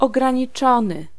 Ääntäminen
IPA : /ˈlɪmɪtɪd/